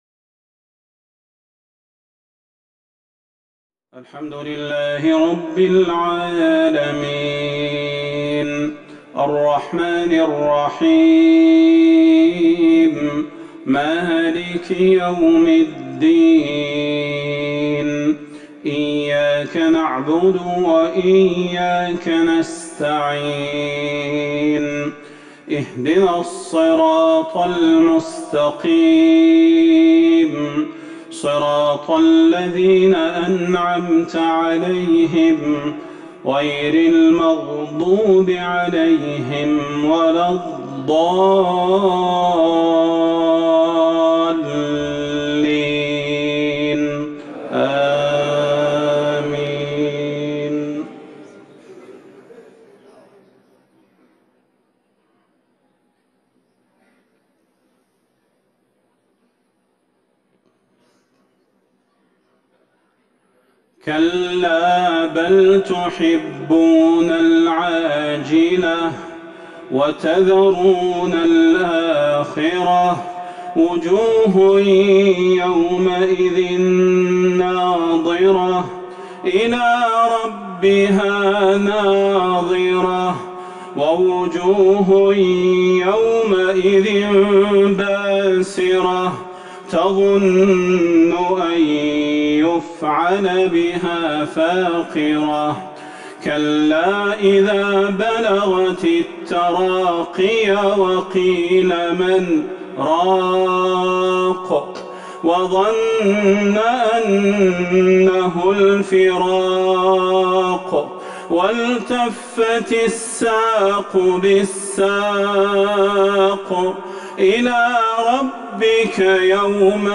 صلاة العشاء ١٢ جمادي الاخره ١٤٤١هـ سورة القيامة Isha prayer 6-2-2020 from Surah Al-Qiyamah > 1441 🕌 > الفروض - تلاوات الحرمين